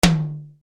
01W TOM.wav